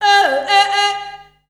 Index of /90_sSampleCDs/Voices_Of_Africa/SinglePhrasesFemale
19_Yodel4.WAV